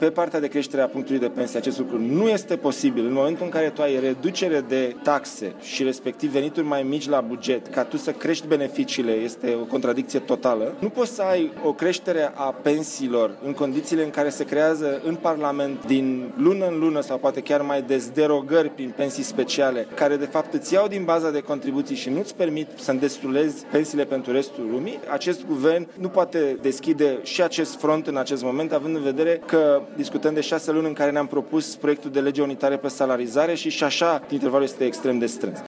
Ministrul Muncii, Dragoş Pâslaru, a explicat, însă, pentru Radio România Actualităţi, că actualul executiv nu are la dispoziţie, în acest moment, resurse pentru a majora pensiile: